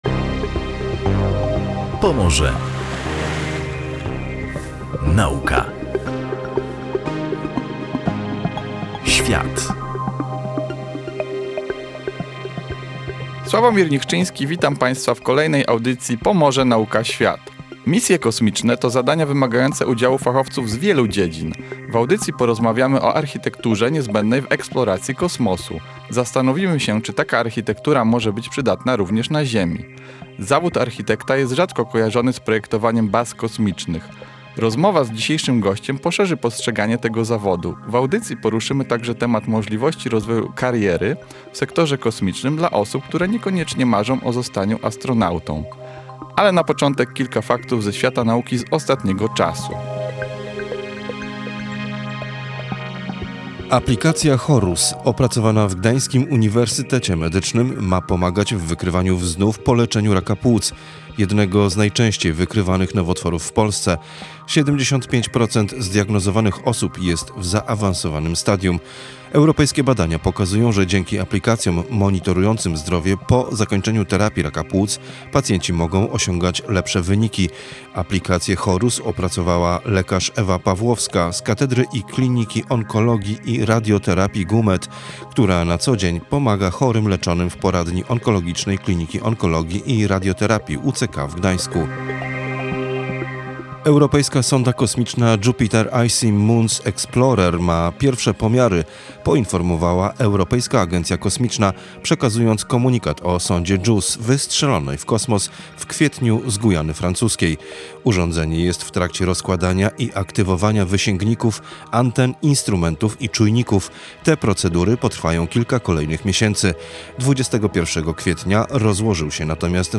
Misje kosmiczne to zadania wymagające fachowców z wielu dziedzin. W audycji „Pomorze Nauka Świat” rozmawialiśmy o architekturze, która jest niezbędna do eksploracji kosmosu, a także pytaliśmy, czy taka architektura może być przydatna na ziemi.